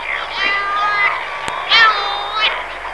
Gelbschnabel-Sturmtaucher (Calonectris diomedea)
Cory's Shearwater
Stimme: mövenartige, jaulende Rufe.
Calonectris.diomedea.wav